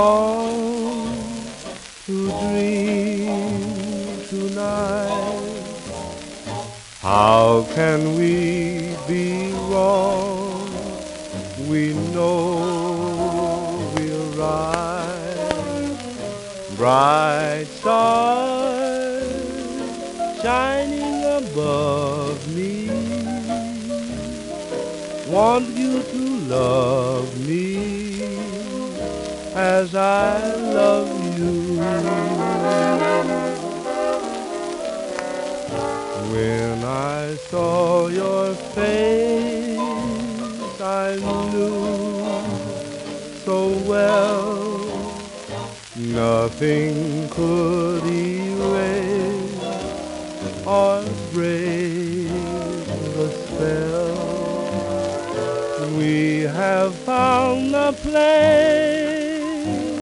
盤質:B *盤面スレ、小キズ、サーフェイスノイズ、レーベル汚れ
1938年頃の録音